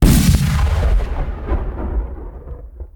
beamcannon.ogg